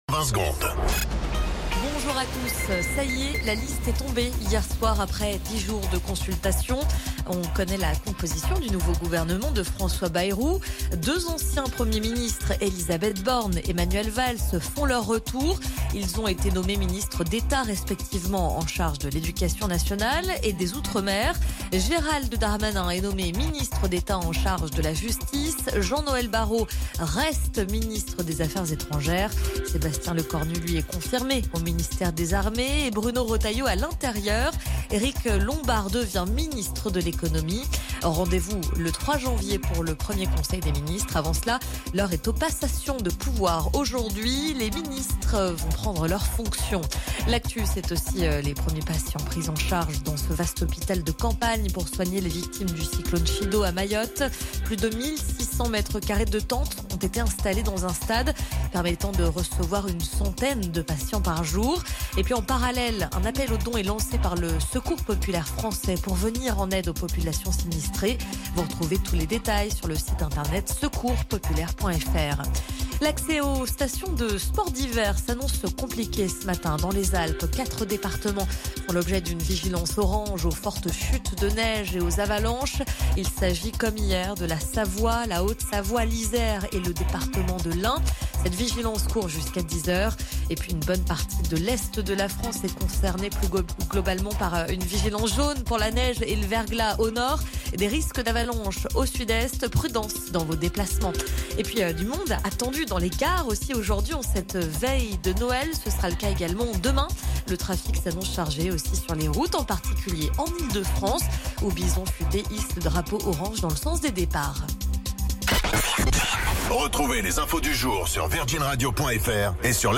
Flash Info National 24 Décembre 2024 Du 24/12/2024 à 07h10 .